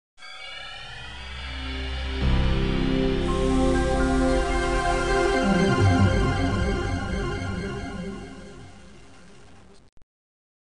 Звуки приветствия Windows